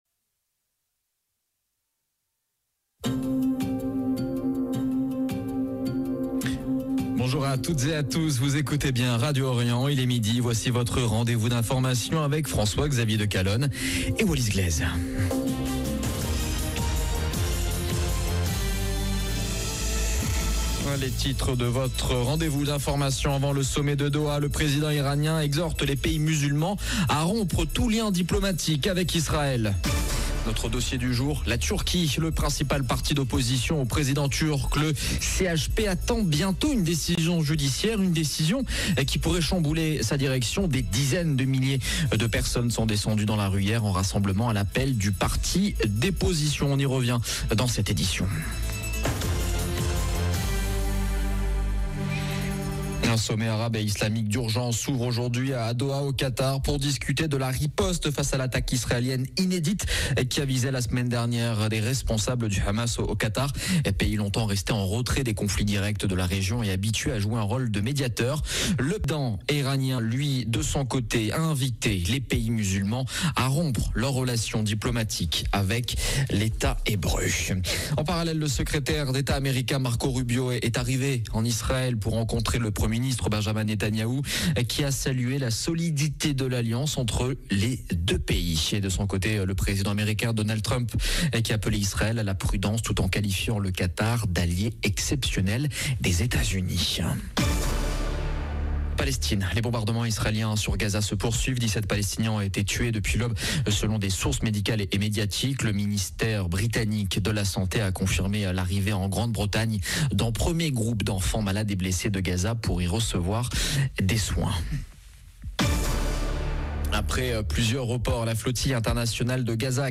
Journal de midi du 15 septembre 2025